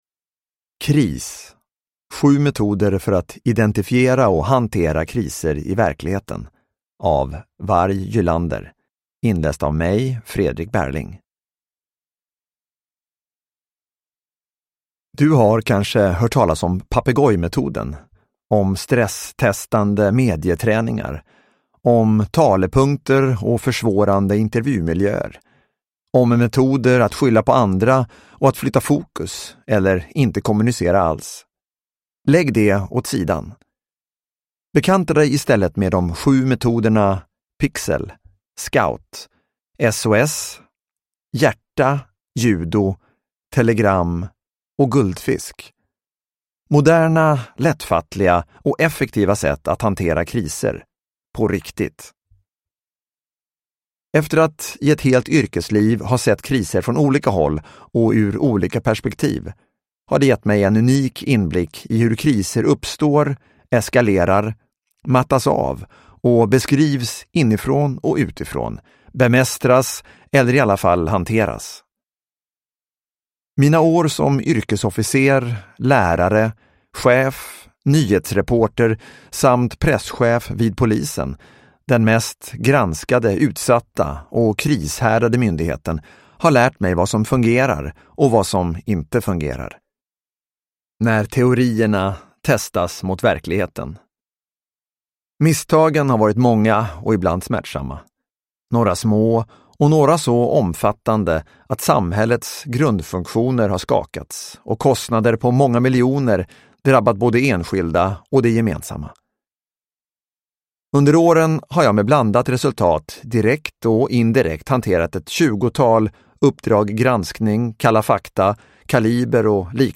Kris - 7 metoder för att identifiera och hantera kriser i verkligheten – Ljudbok – Laddas ner